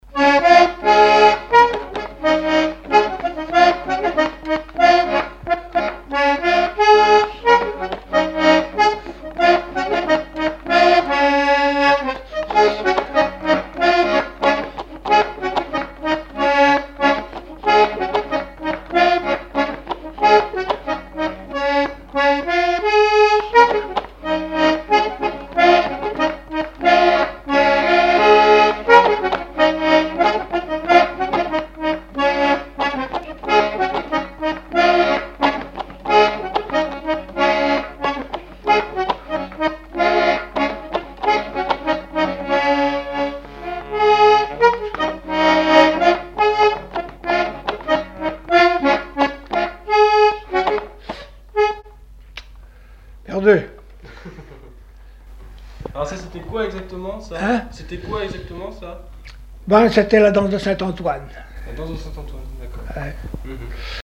danse : scottich trois pas
Répertoire instrumental à l'accordéon diatonique
Pièce musicale inédite